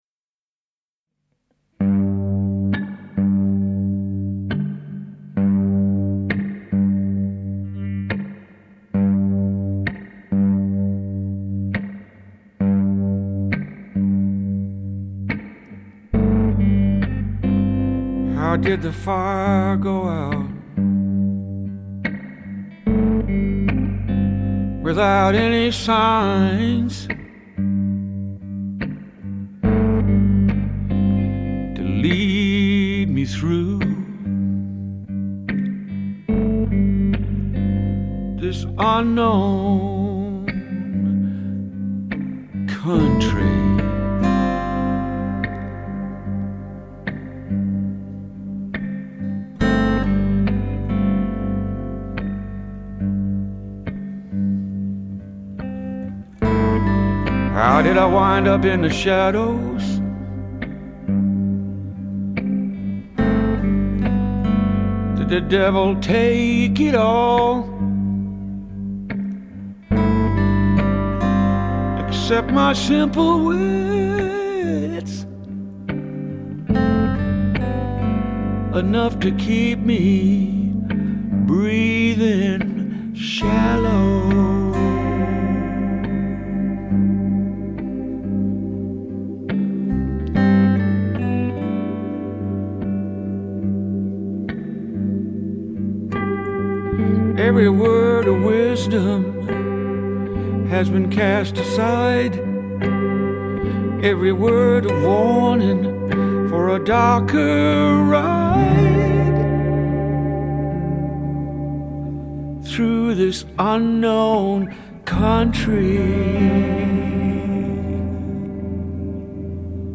acoustic guitars, pump organ and harmonica